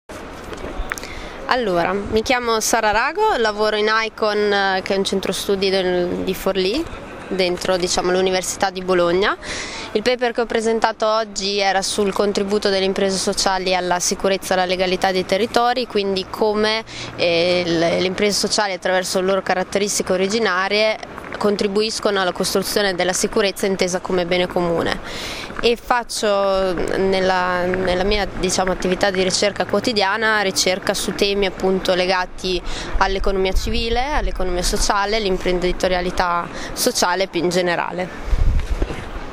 Il Colloquio Scientifico sull’impresa sociale, edizione VII, si è chiuso la scorsa settimana a Torino.
A ricordo di alcuni dei loro interventi, delle brevi audio interviste mordi e fuggi sui loro temi di ricerca.